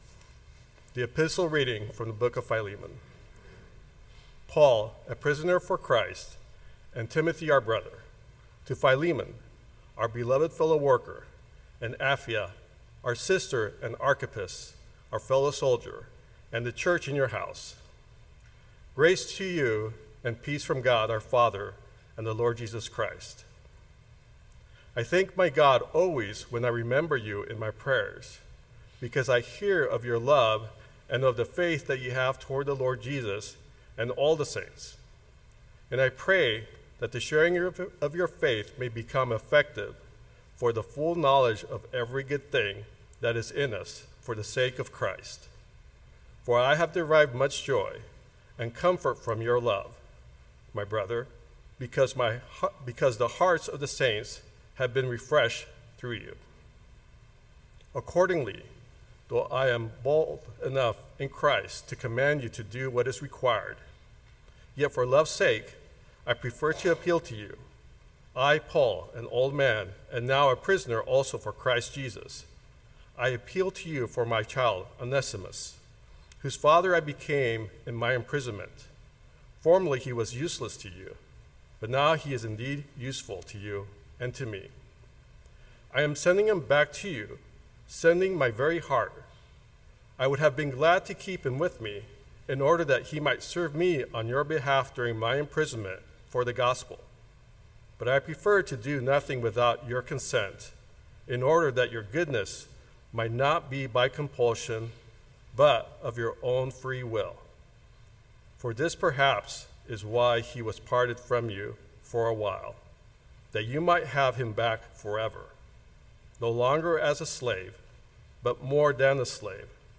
090725 Sermon Download Biblical Text: Philemon 1-21, (Luke 14:25-35 as background) The letter to Philemon is to me almost the proof of the entire bible.